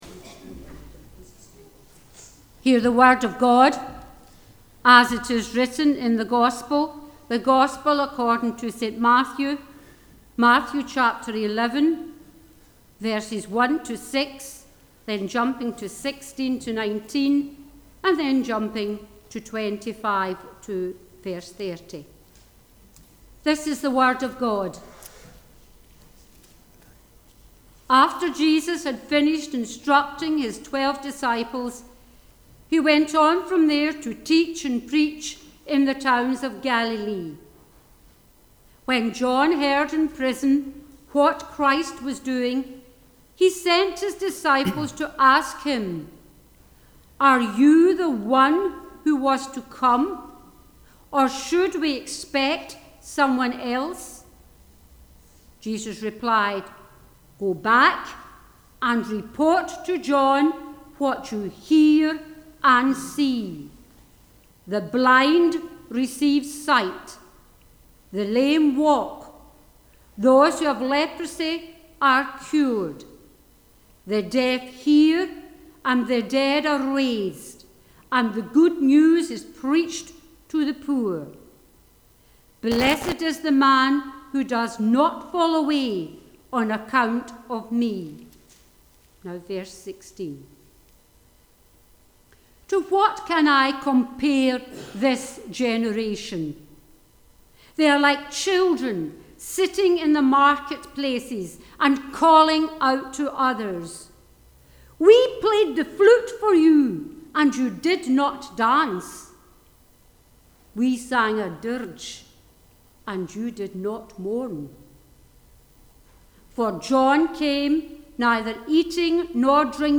Online Worship